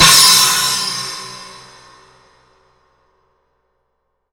PERC.48.NEPT.wav